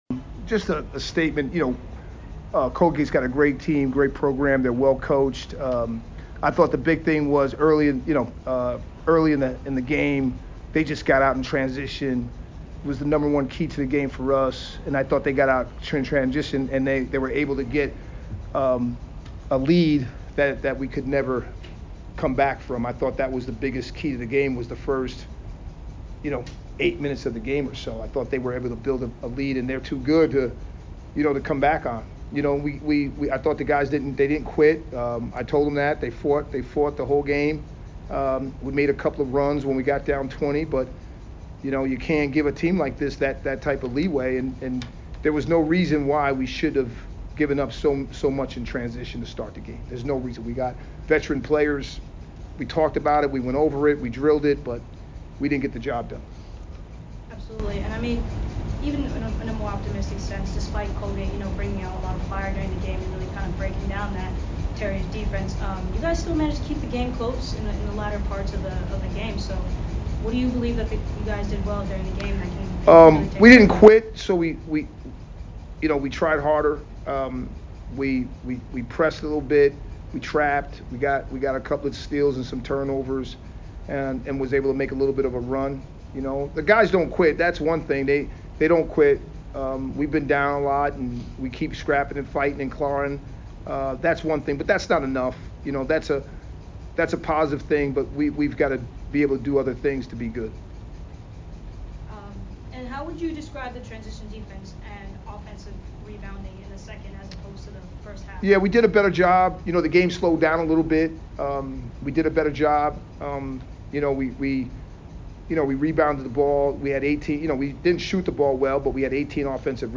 Men's Basketball / Colgate Postgame Interview (1-23-23) - Boston University Athletics